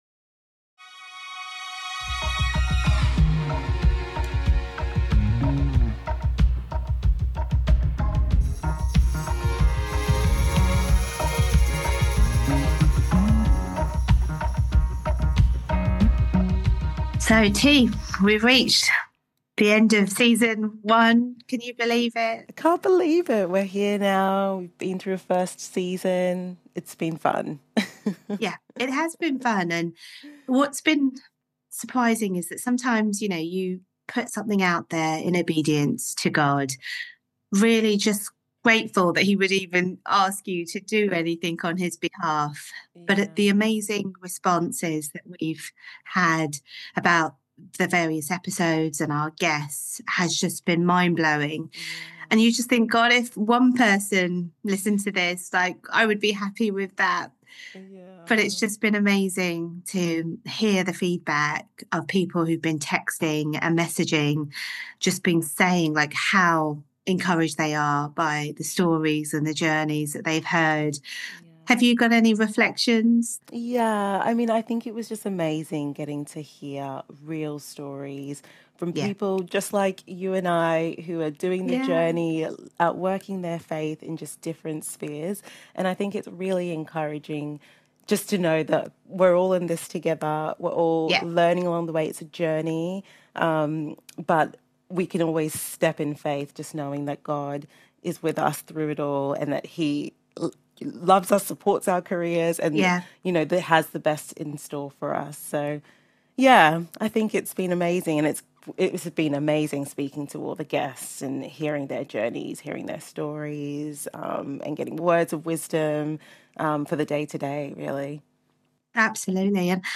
We close the episode, and the season, with Christmas greetings, messages from guests and listeners, and a reading from Isaiah 9:6–7, reminding us of the peace, hope, and promise found in Jesus.